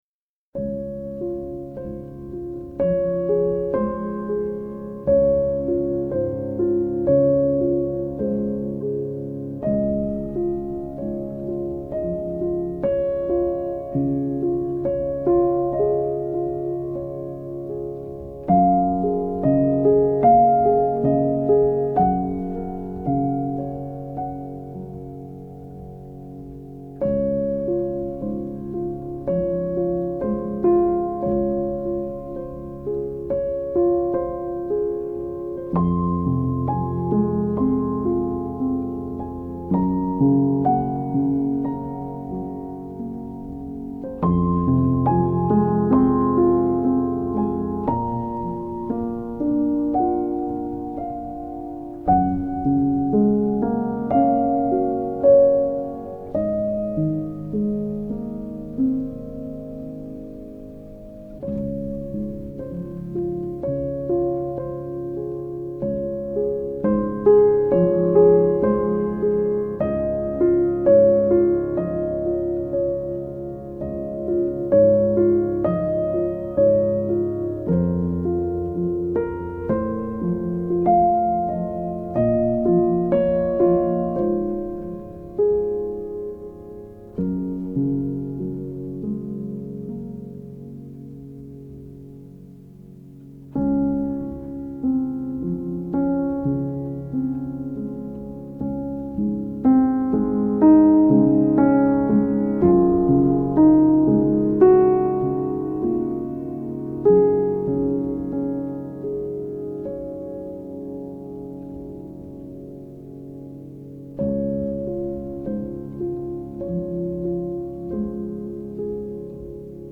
سبک آرامش بخش , پیانو , عصر جدید , موسیقی بی کلام
پیانو آرامبخش